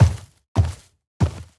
sounds / mob / sniffer / step2.ogg
step2.ogg